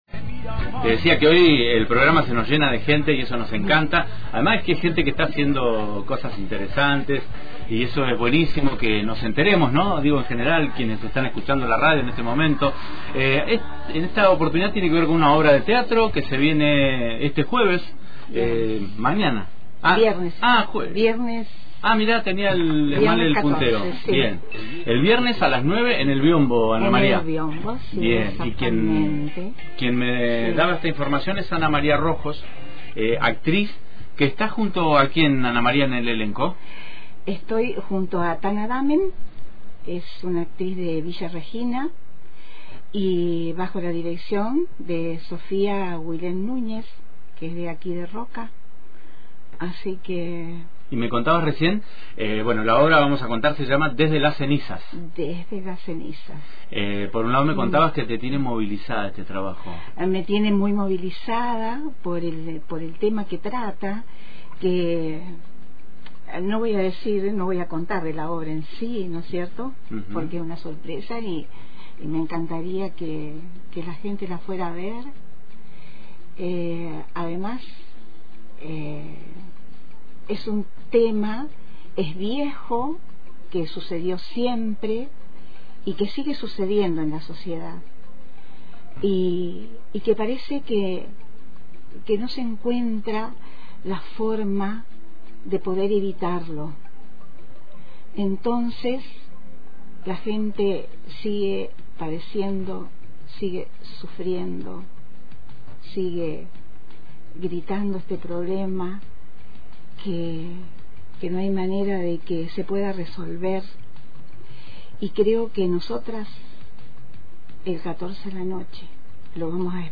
nos habló en la radio